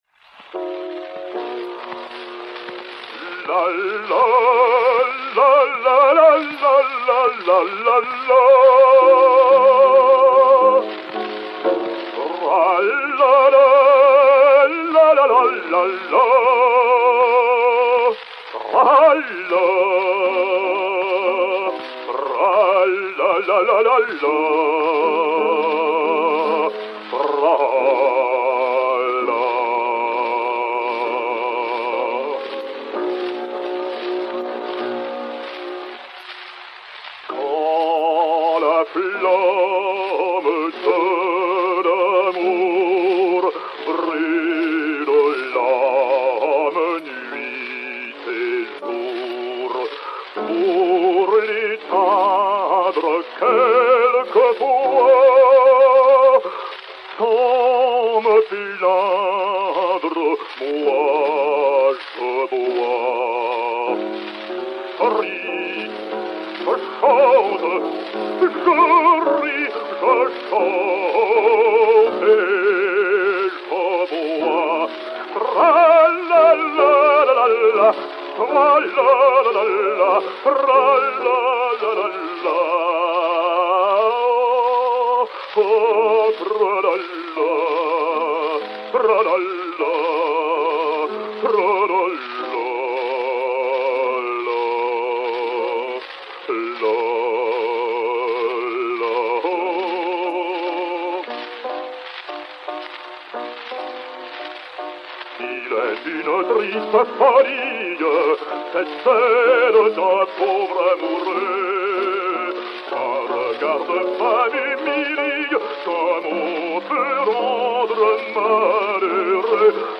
Acte II. Sérénade "A la voix d'un amant fidèle"
Alain Vanzo (Smith) et Orchestre dir Jésus Etcheverry